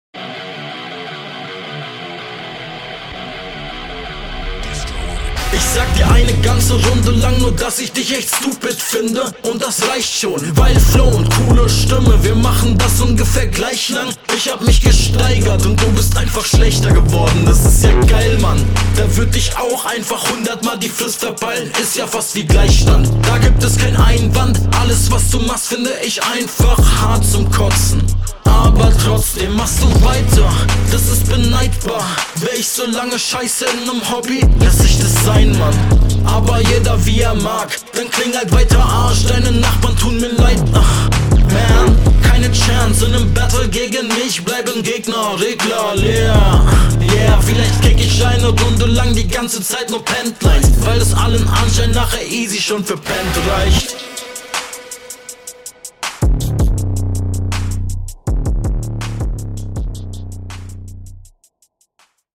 Schöner aggressiver Sound, inhaltlich viel von oben herab, was gut funktioniert, eingängige Reimpatterns